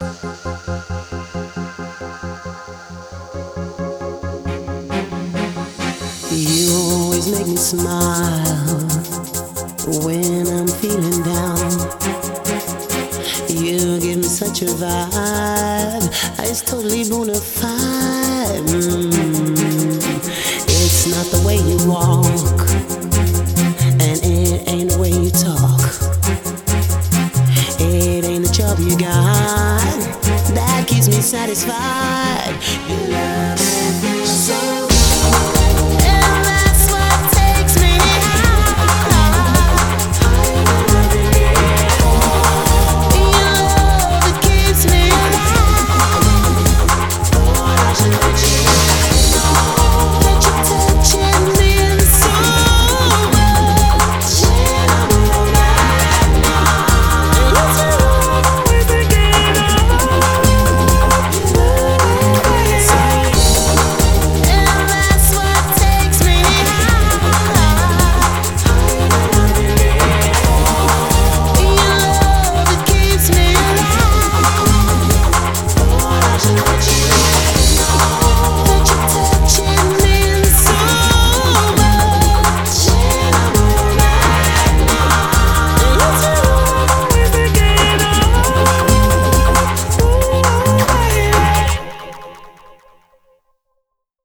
BPM136
Audio QualityPerfect (High Quality)
CommentsGenre: Dance-Pop